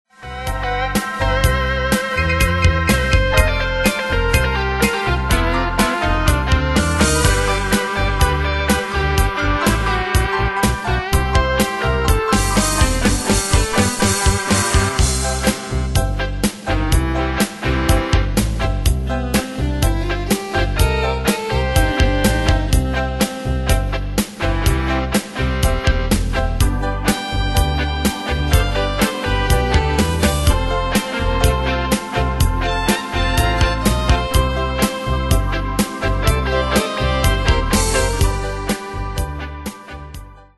Style: Country Année/Year: 1995 Tempo: 125 Durée/Time: 3.22
Danse/Dance: Rock Cat Id.
Pro Backing Tracks